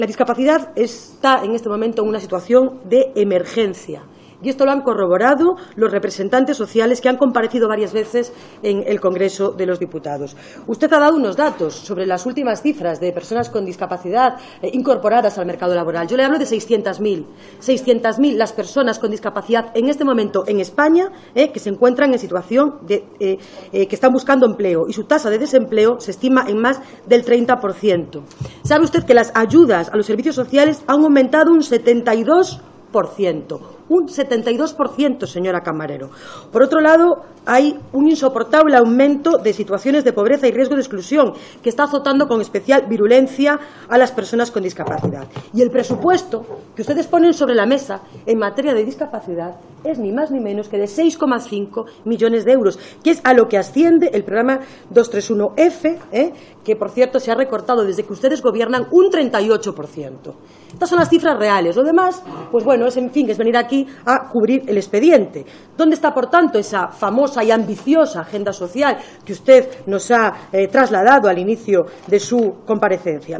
Fragmento de la intervención de Laura Seara en la Comisión de Sanidad y Servicios Sociales.